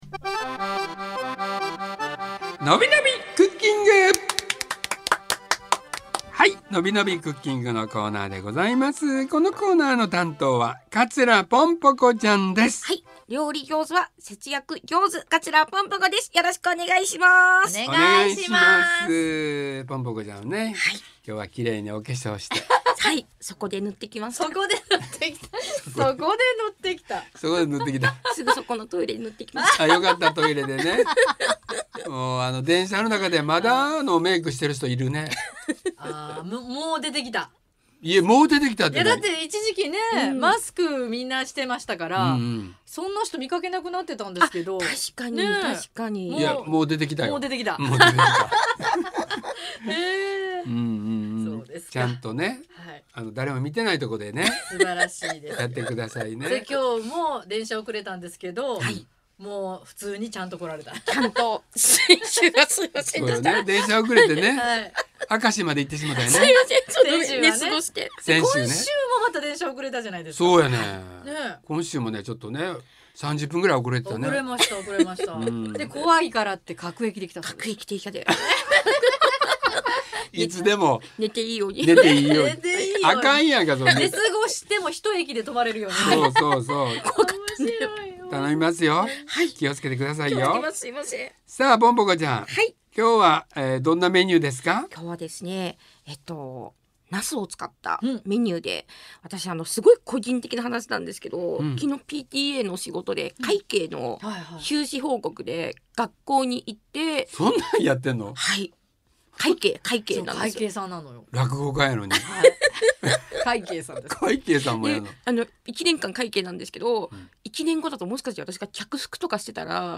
【放送音声】